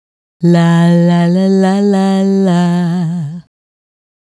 La La La 110-F.wav